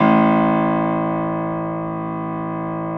53h-pno01-A-1.aif